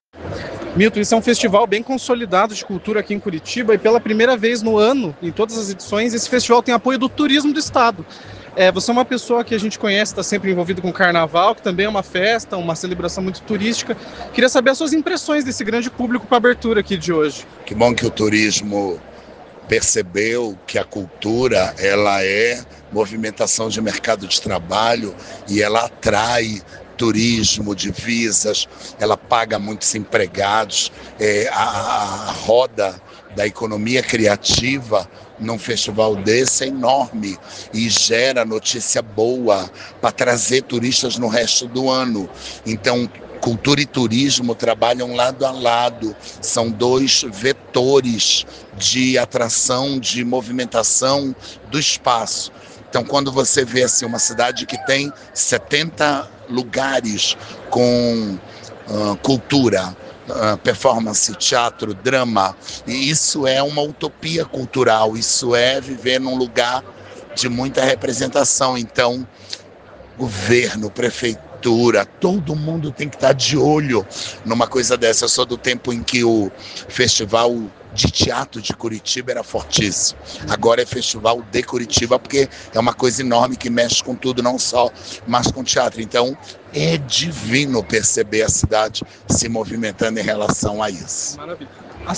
ENTREVISTA MILTON CUNHA